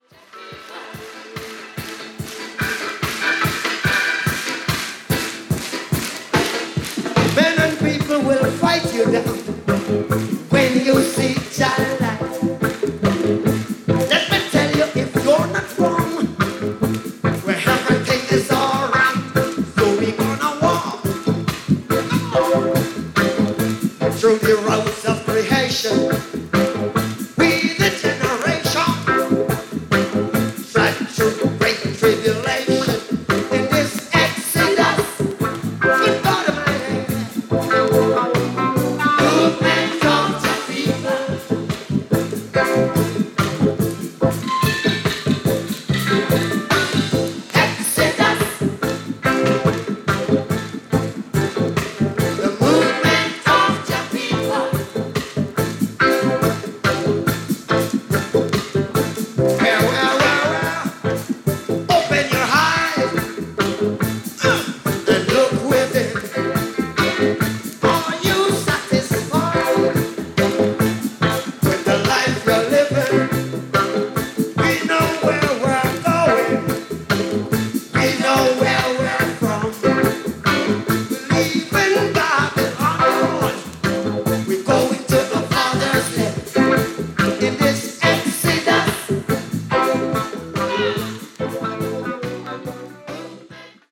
Live Versionで収録された 12"です。